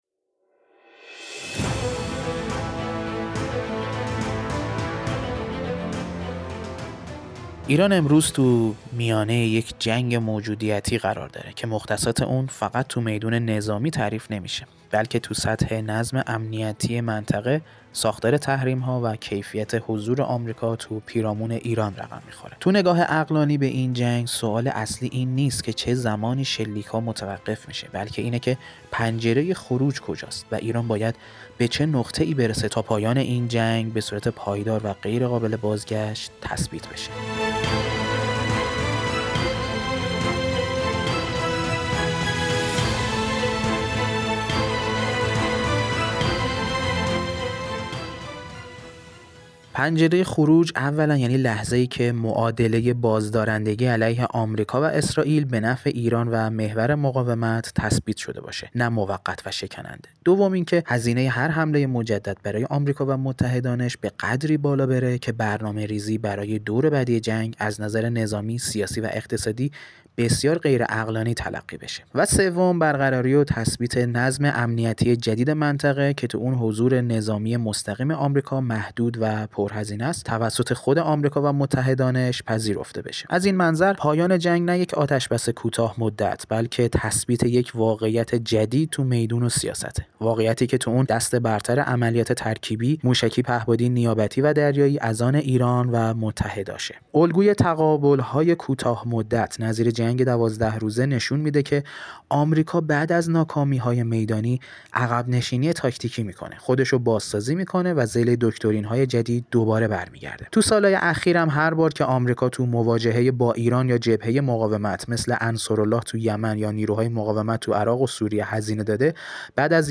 آناکست؛ مستند